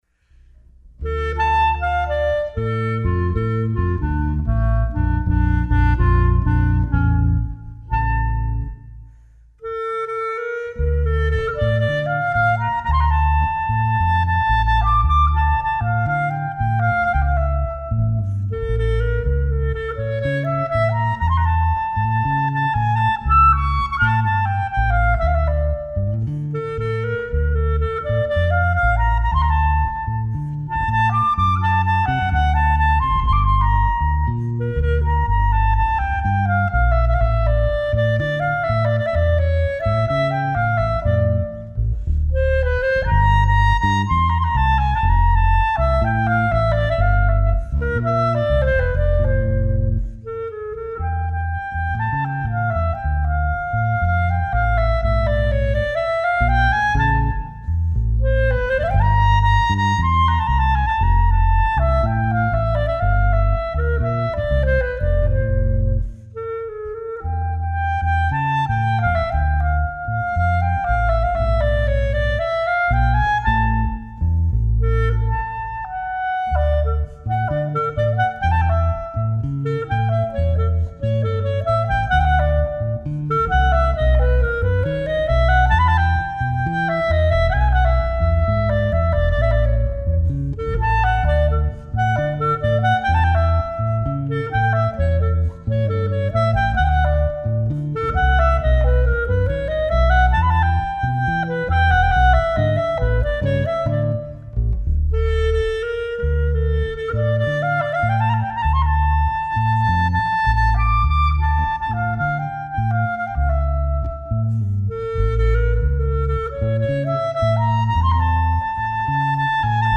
Bass Clarinet &  Contrabass Instrumental Duo
performance excerpts
Bass Clarinet, Soprano Clarinets
Fretted & Fretless 6-stringContrabass Guitars